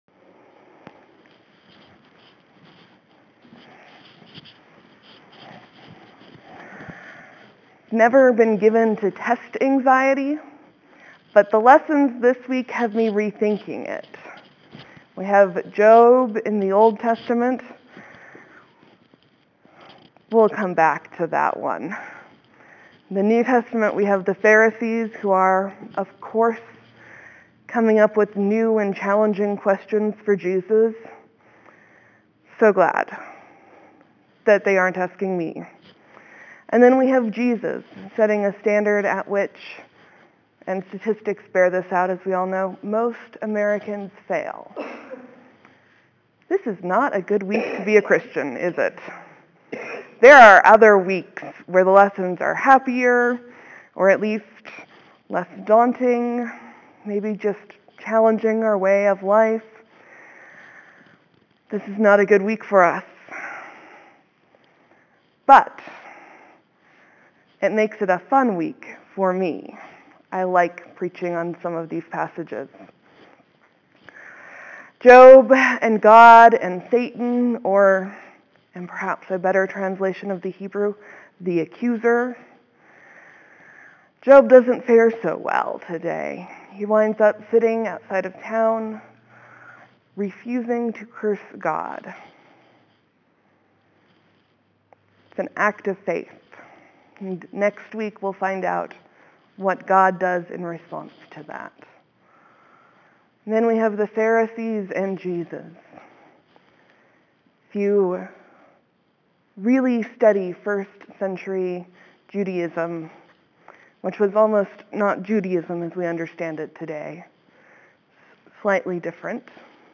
(There will be a few moments of silence before the sermon begins. Thank you for your patience.)